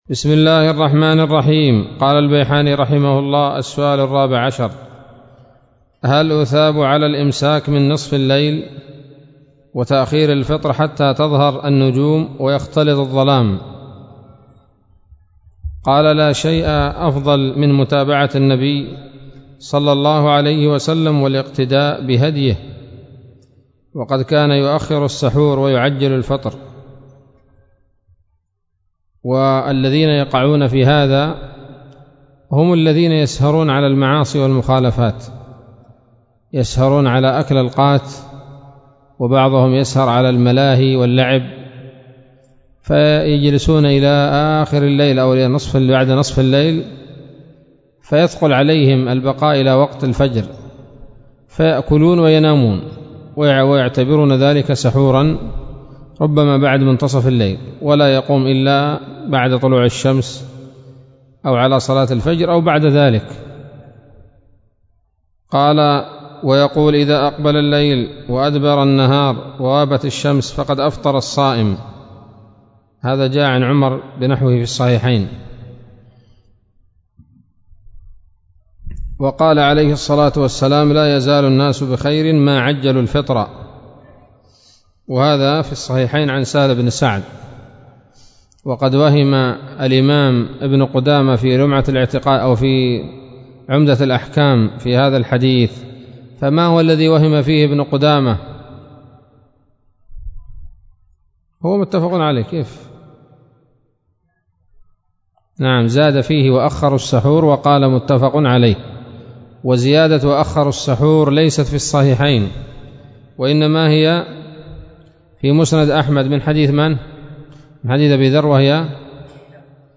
الدرس الثالث عشر من تحفة رمضان للعلامة البيحاني